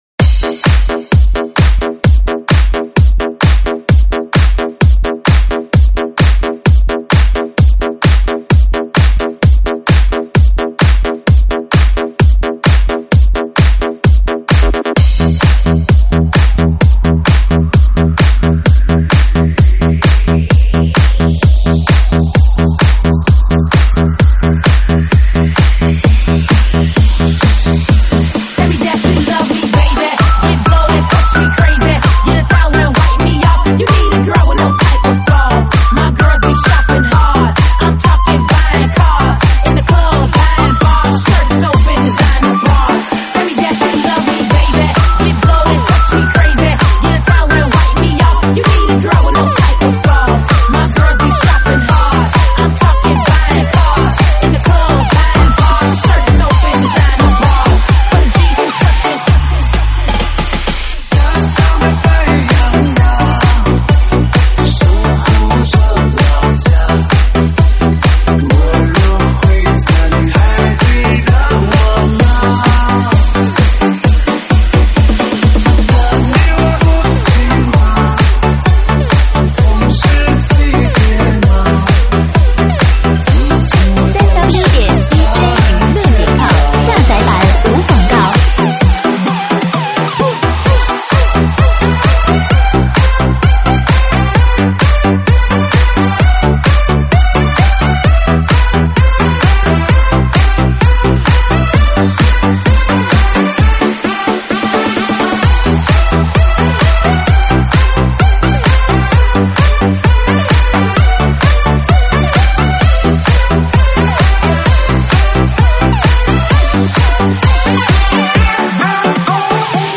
中文慢摇